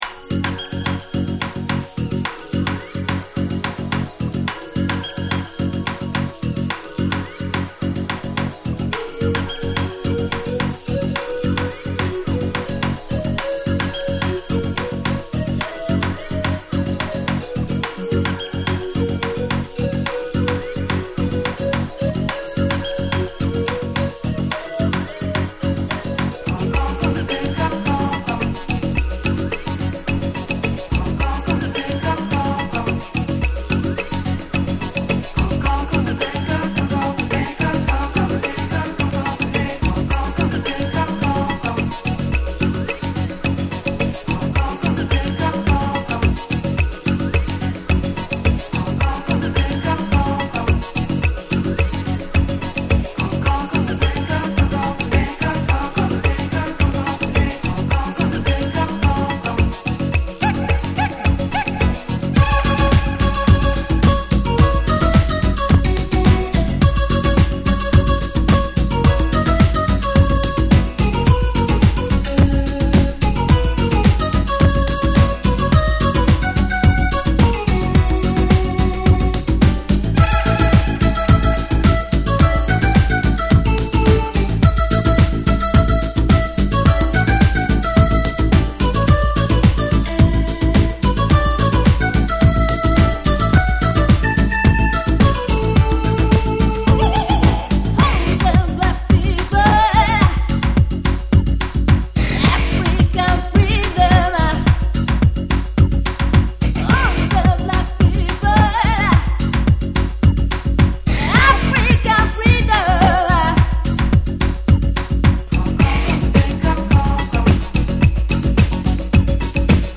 TRIBAL MIX/ TECNO MIX.